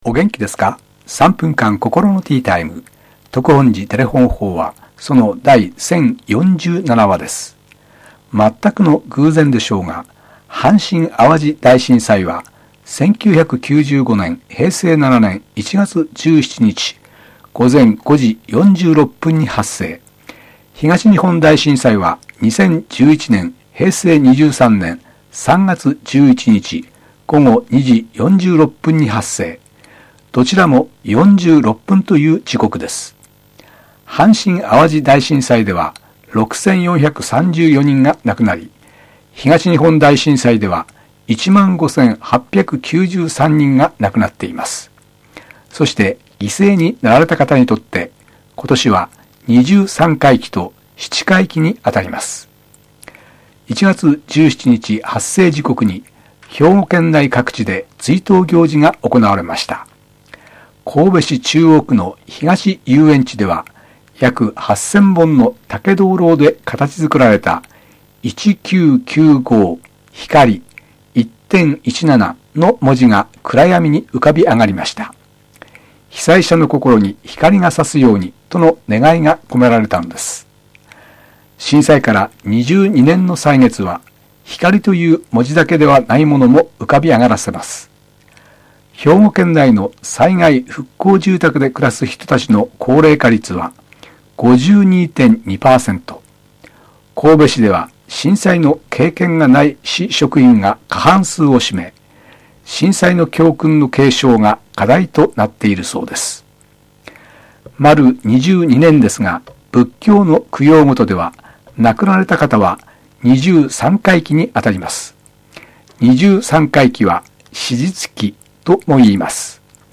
テレホン法話
住職が語る法話を聴くことができます